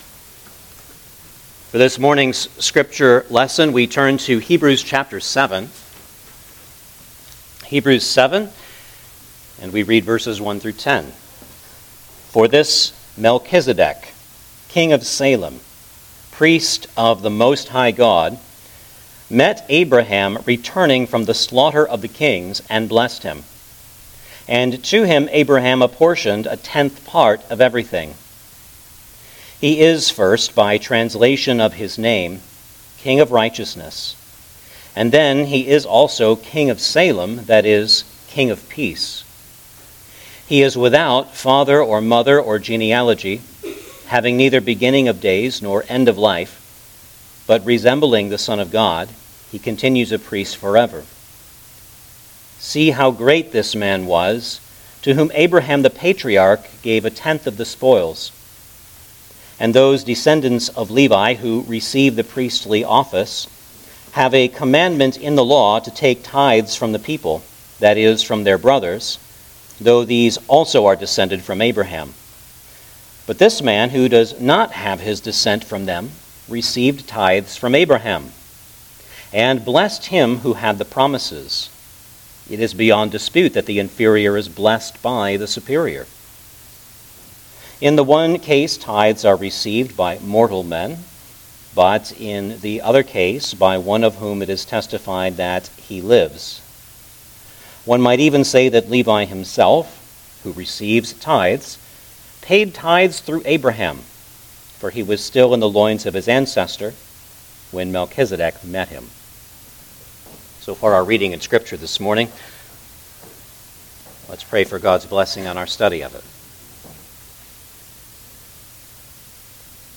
Hebrews Passage: Hebrews 7:1-10 Service Type: Sunday Morning Service Download the order of worship here .